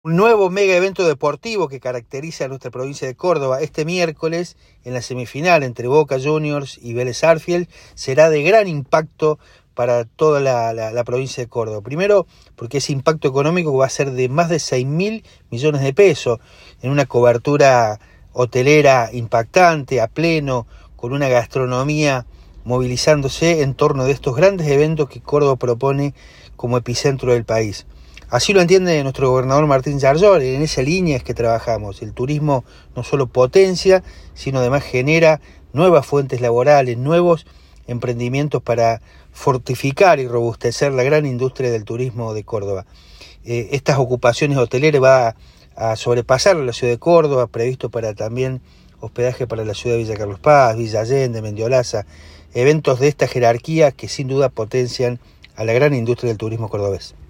Darío Capitani, presidente de la Agencia Córdoba Turismo.